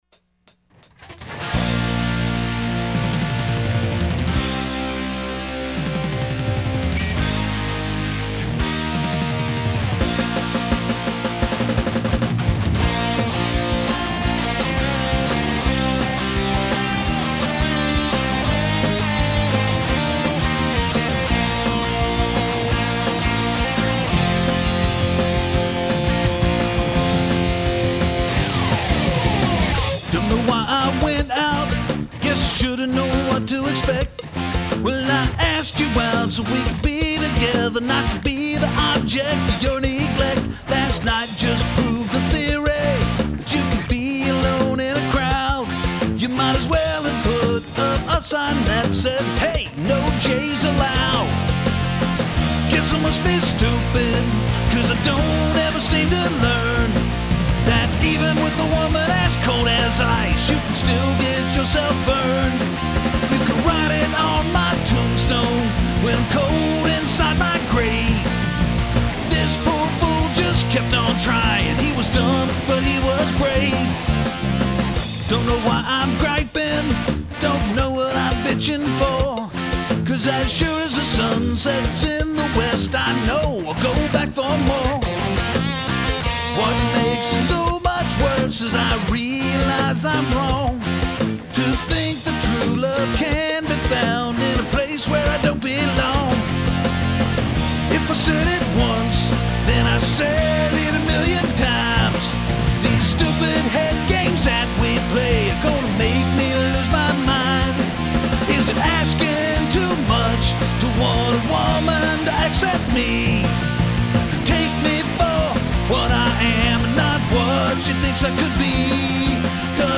Conservative rock, Boise